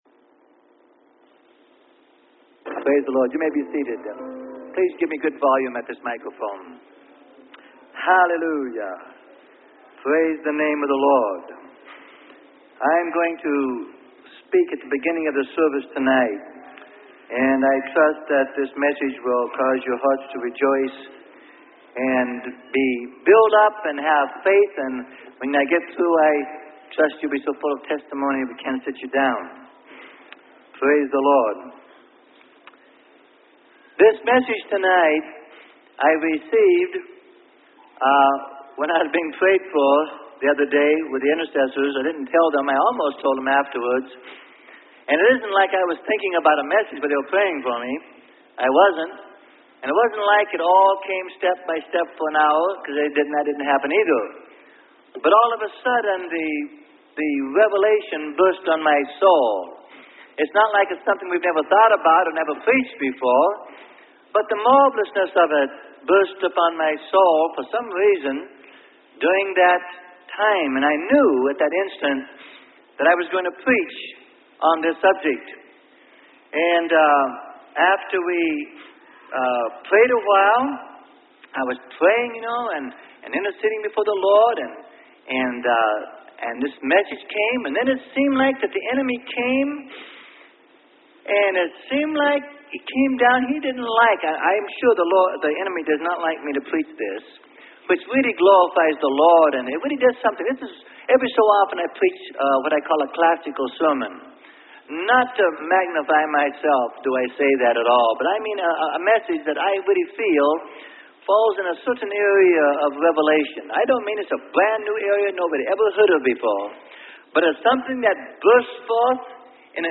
Sermon: God, Willing To Include Man With Him.